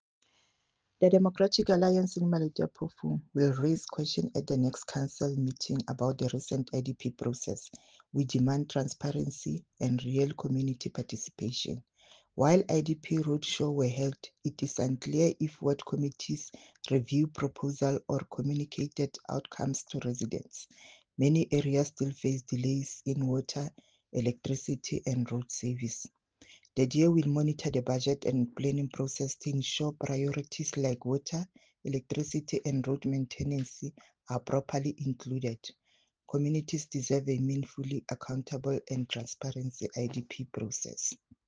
Sesotho soundbites by Cllr Ana Motaung and